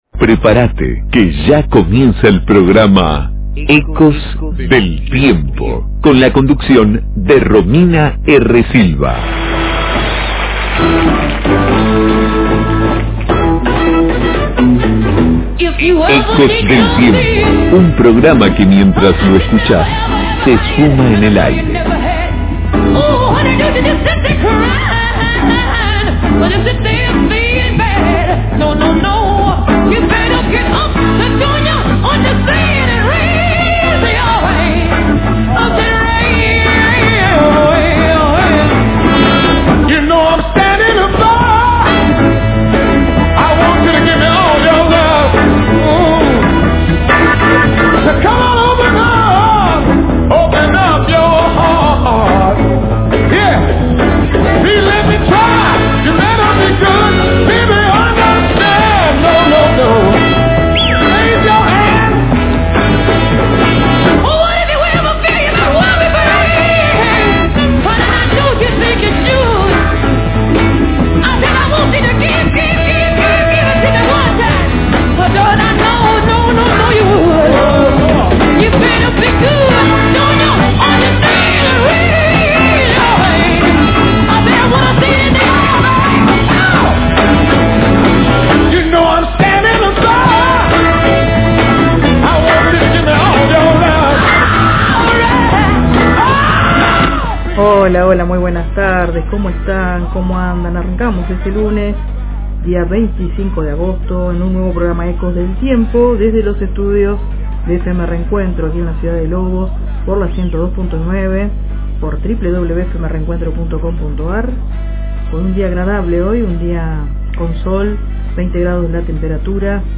✨ Entrevistas